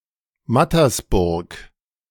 Mattersburg (German pronunciation: [ˈmatɐsˌbʊʁk]
De-Mattersburg.ogg.mp3